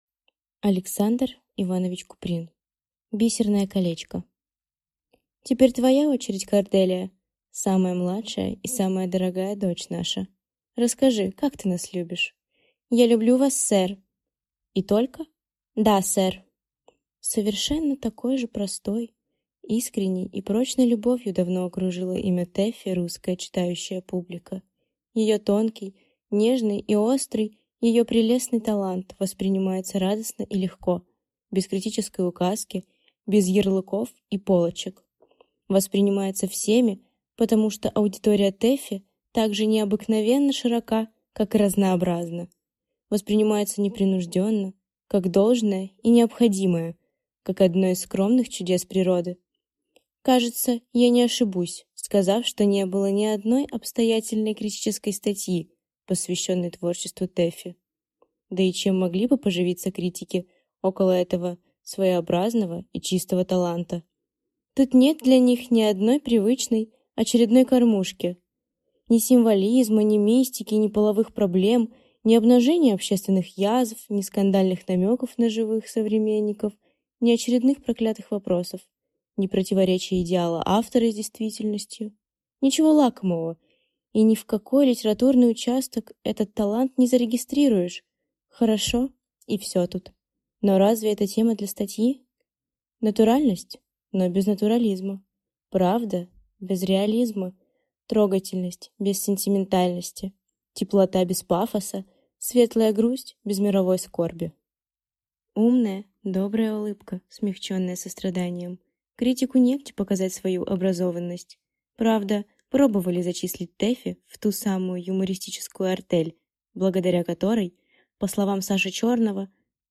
Аудиокнига Бисерное колечко | Библиотека аудиокниг